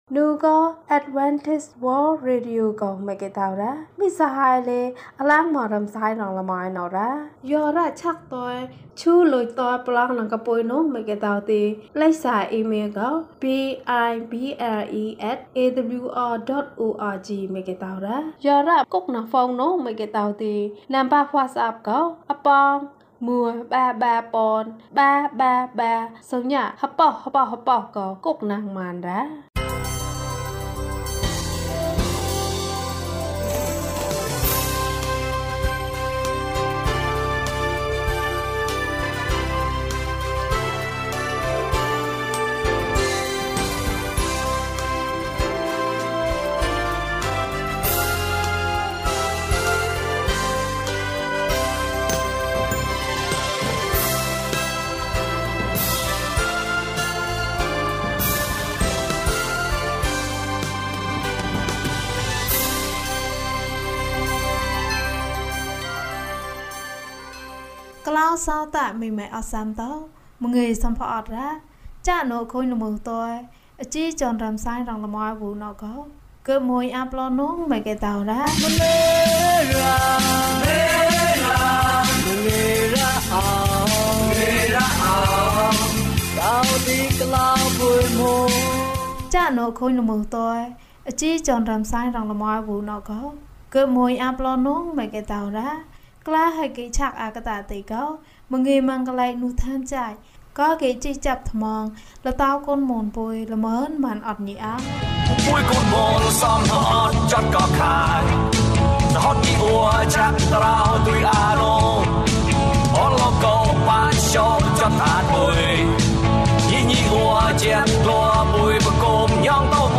ဘုရားသခင် သင်နှင့်အတူ အချိန်တိုင်း။၀၂ ကျန်းမာခြင်းအကြောင်းအရာ။ ဓမ္မသီချင်း။ တရားဒေသနာ။